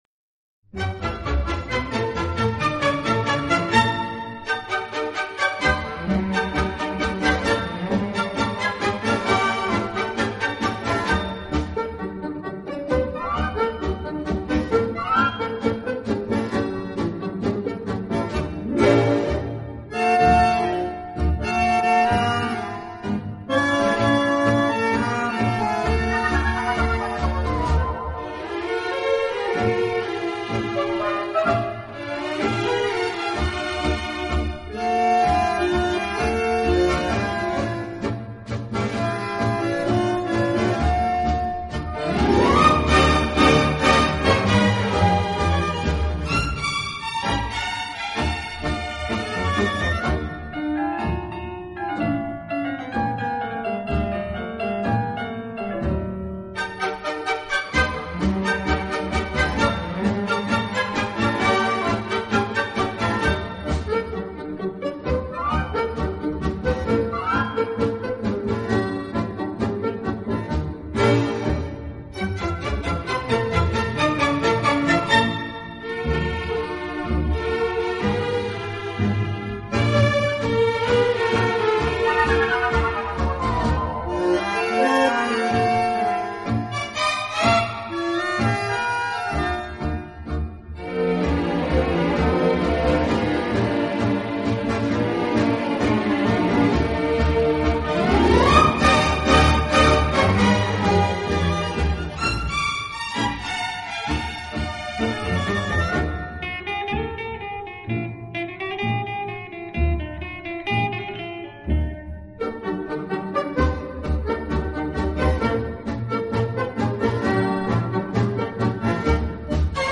【轻音乐】
乐背景的不同，以各种乐器恰到好处的组合，达到既大气有力又尽显浪漫的效果。
乐队的弦乐柔和、优美，极有特色，打击乐则气度不凡，而手风琴、钢琴等乐器